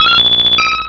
-Replaced the Gen. 1 to 3 cries with BW2 rips.
persian.aif